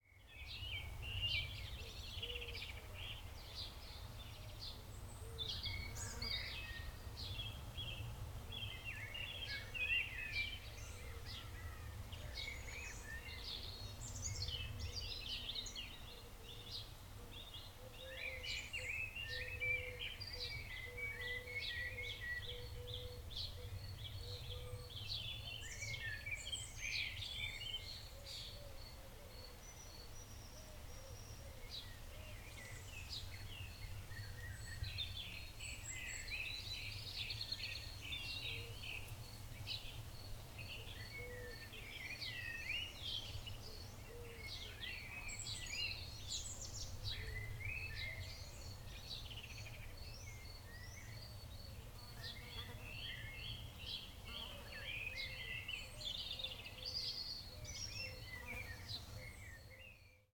Il y avait une certaine qualité de paysage sonore ; ce n’était pas encore le calme de la nuit, toujours un bruit de fond urbain au loin (l’oreille s’en accommode, le micro, moins), et d’avions qui passent. Mais les insectes, les oiseaux, ça dégageait une impression de calme et de sérénité.
Je suis rentré chercher l’enregistreur et l’ai laissé tourner près du puits, pendant le dîner.